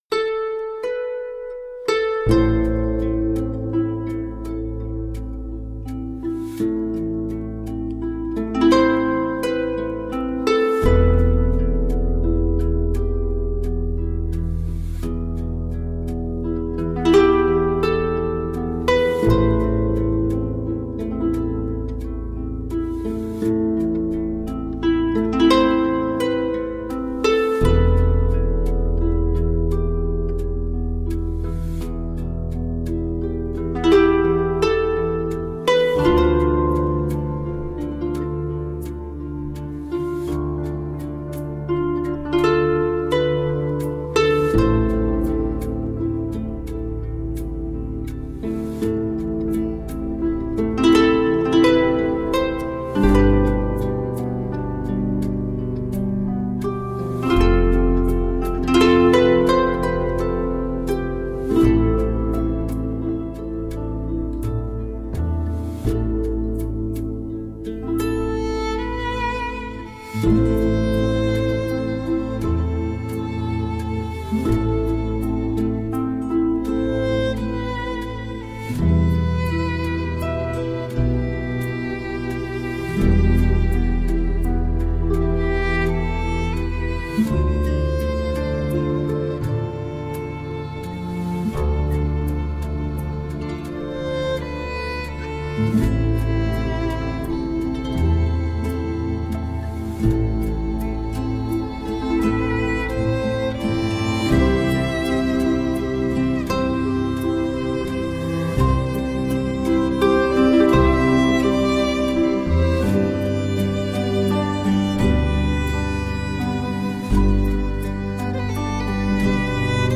Tagged as: World, Folk, New Age, Celtic, Harp